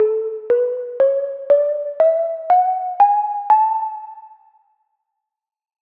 Major
2025-kpop-scale-major.mp3